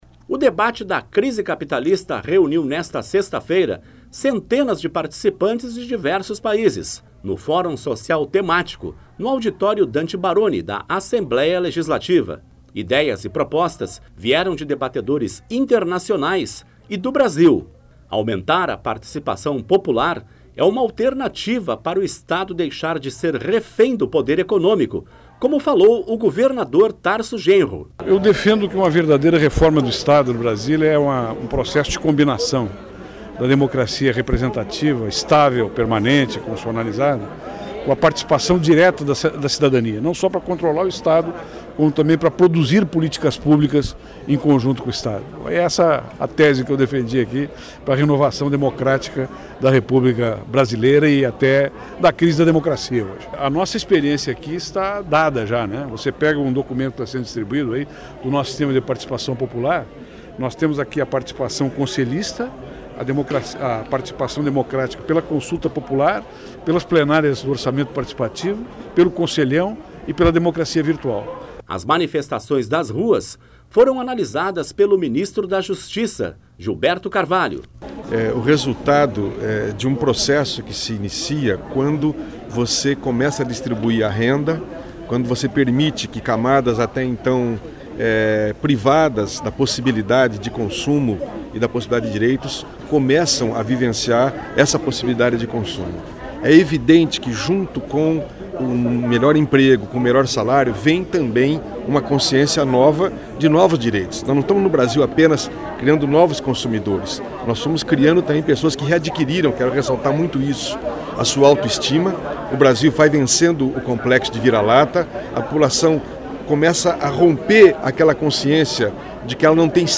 Tarso Genro debate crise capitalista no Fórum Social Temático
O debate da crise capitalista reuniu nesta sexta-feira (24) centenas de participantes, de diversos países, no Fórum Social Temático (FST)realizado no auditório Dante Barone, da Assembleia Legislativa.